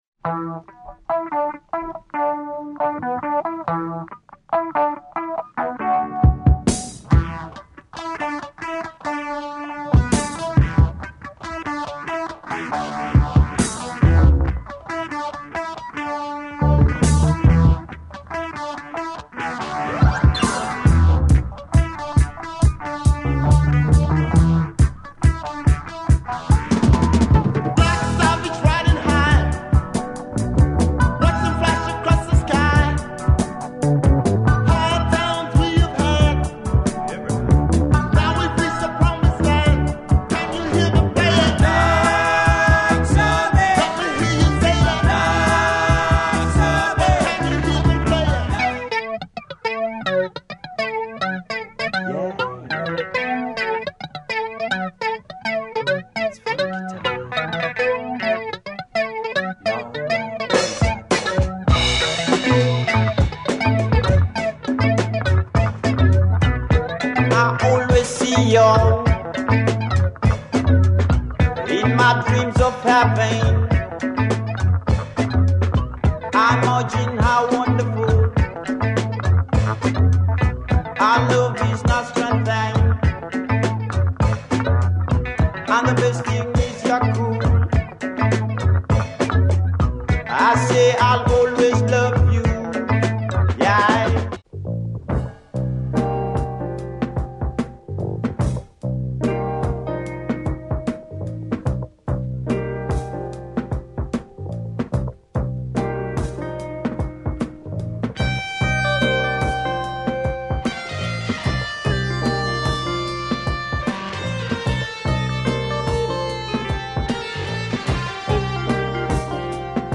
Kenyan Afro-Rock group